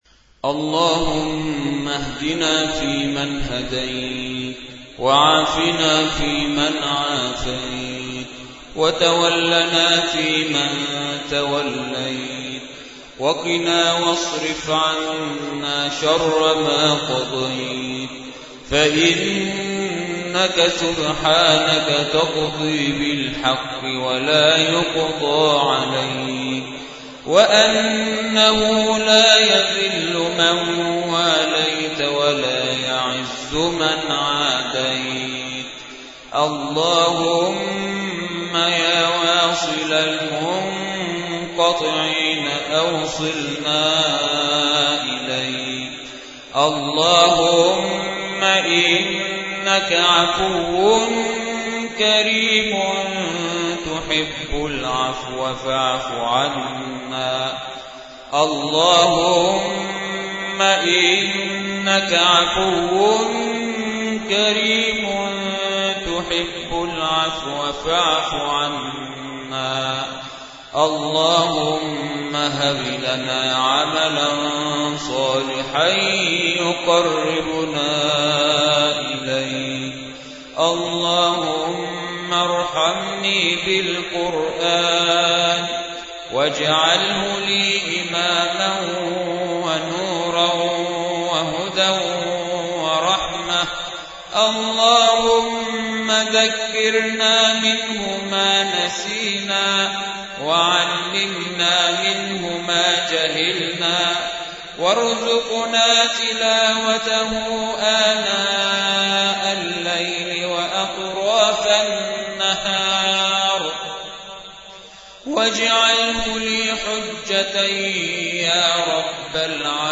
أدعية وأذكار
دعاء خاشع ومؤثر
تسجيل لدعاء خاشع ومميز